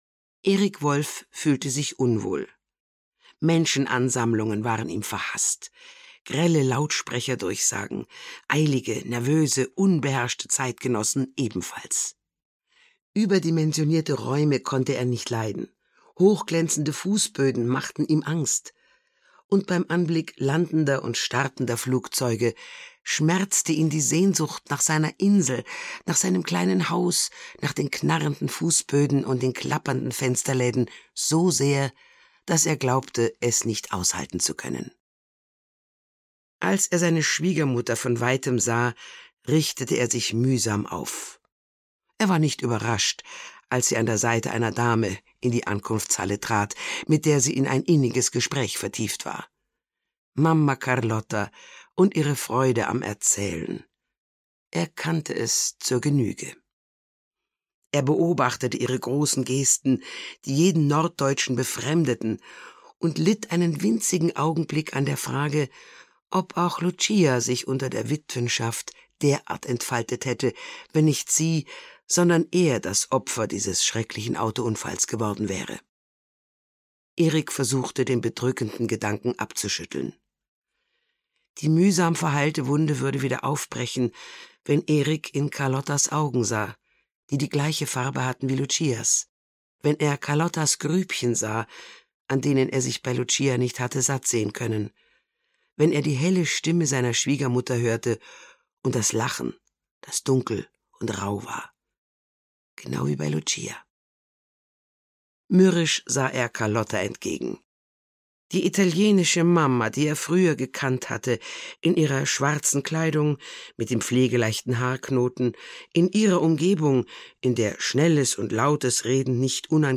Gestrandet (Mamma Carlotta 2) - Gisa Pauly - Hörbuch